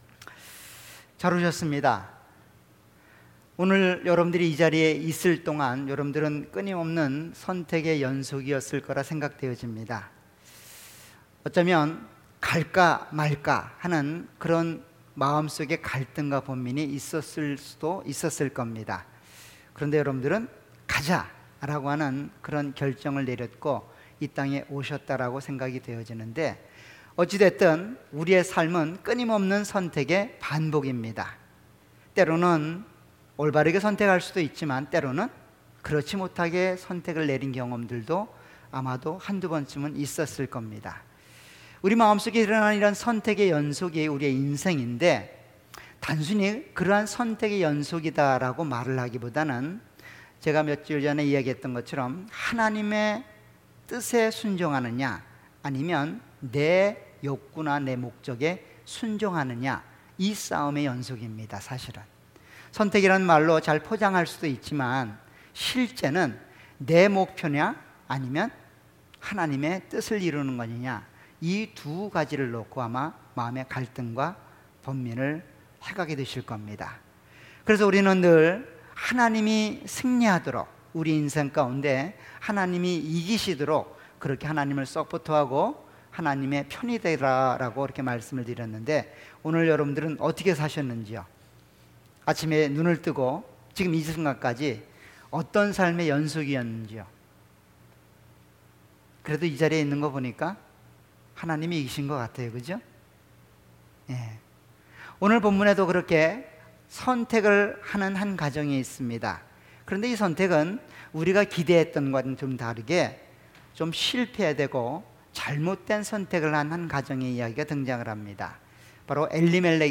All Sermons
Series: 수요예배.Wednesday